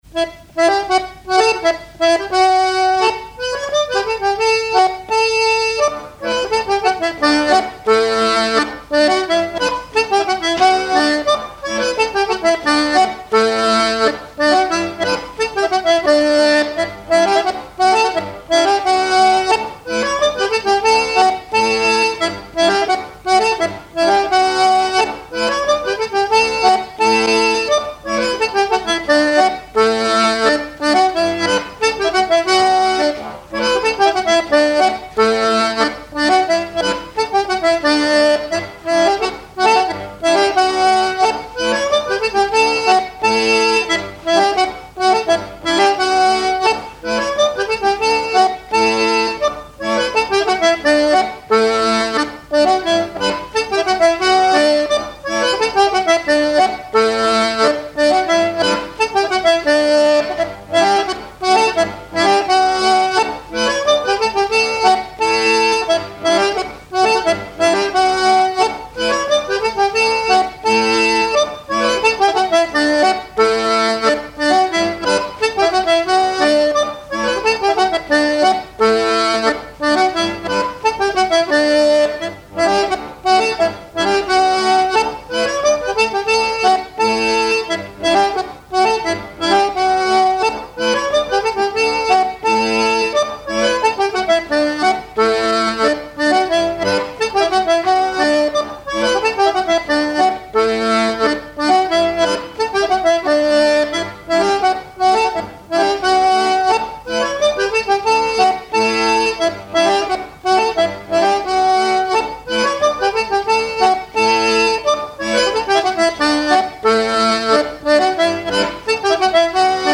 pas d'été
airs de danse à l'accordéon diatonique
Pièce musicale inédite